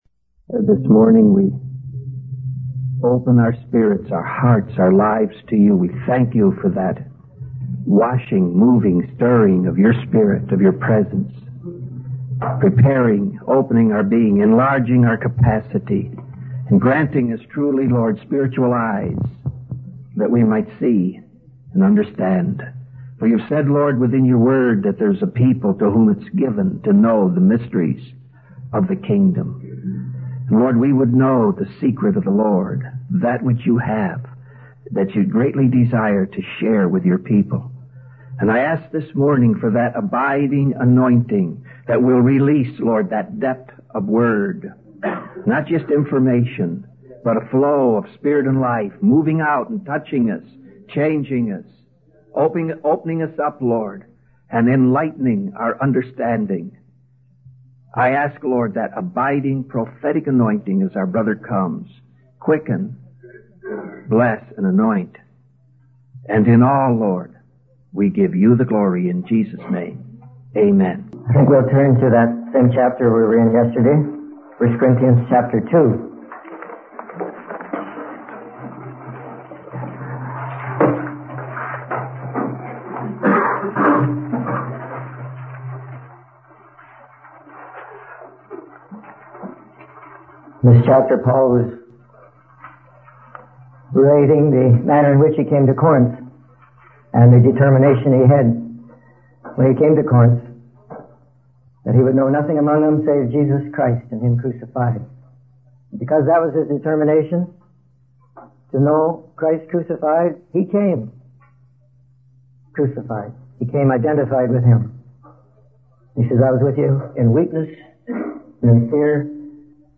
In this sermon, the speaker emphasizes the importance of living in the realm of God's gifts and abiding in Him. He references the story of Caleb and Joshua returning from Canaan with a foretaste of the promised land, highlighting the excitement and anticipation they felt. The speaker also discusses the concept of abiding in God, drawing from John 15 and emphasizing the need to rely on Him completely.